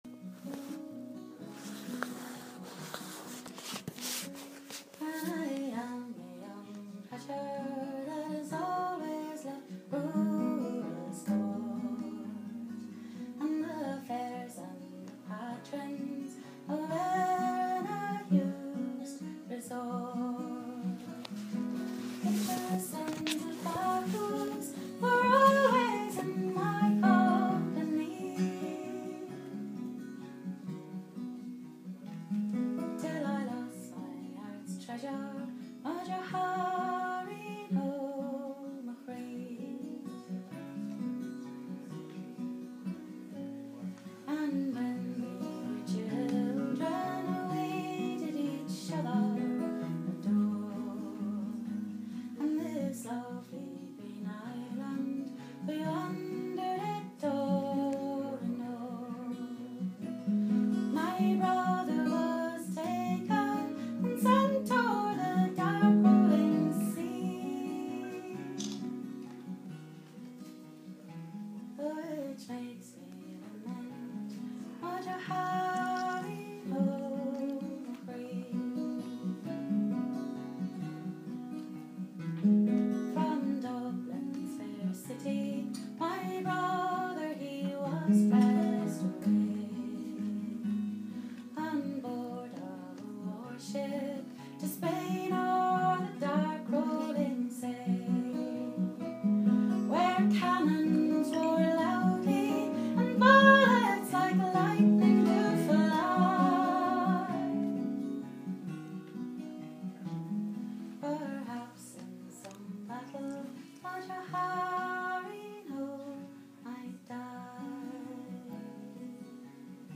Music & Poetry in Ballymaloe Hotel